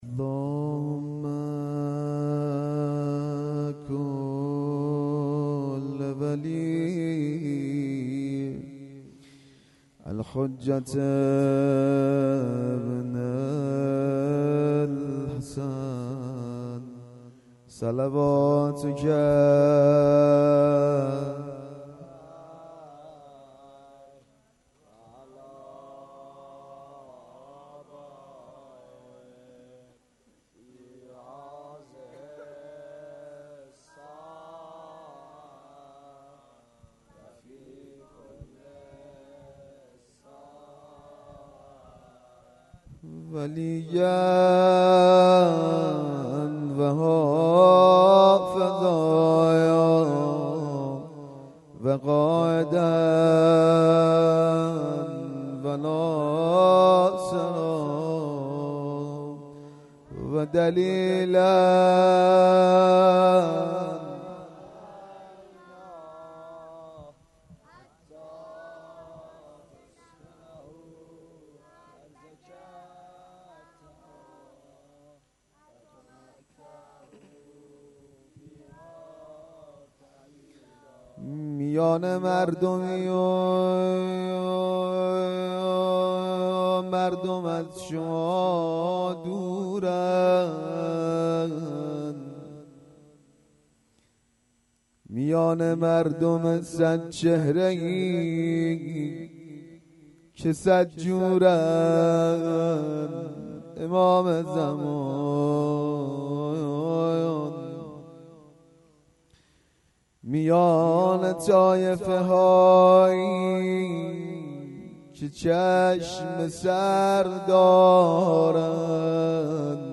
روضه_شب هفتم
هــــيأت سـائلـین حضرت زهـــــرا "س"